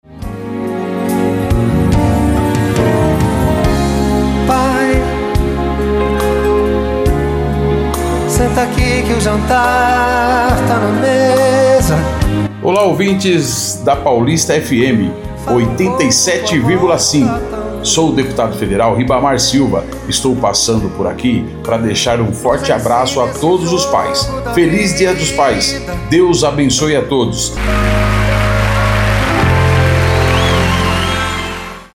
DEPUTADO FEDERAL RIBAMAR SILVA DEIXA SUA MENSAGEM A TODOS OS PAIS